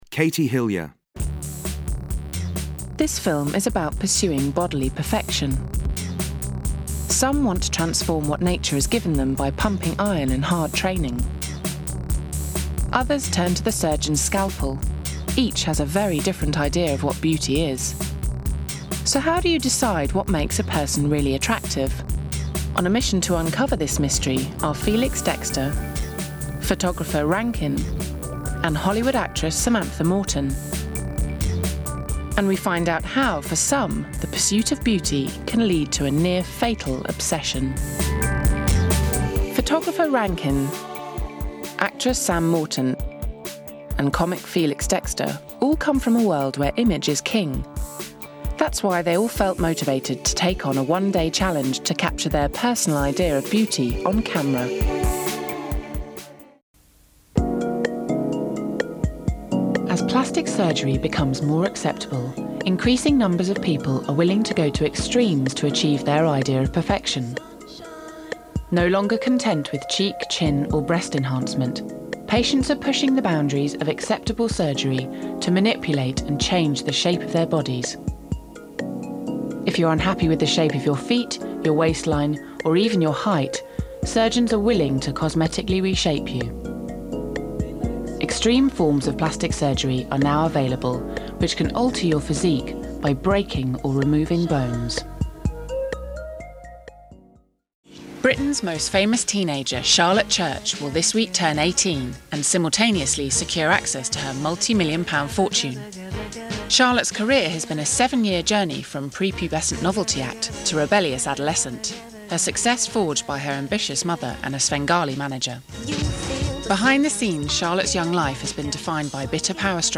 • Female
• Standard English R P